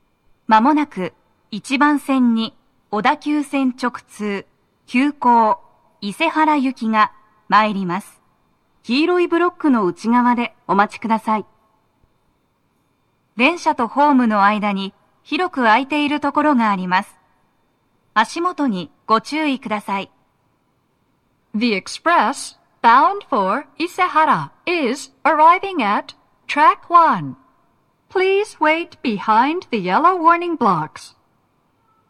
1番線の鳴動は、かなり遅めで、停車駅案内はありません。
女声
接近放送4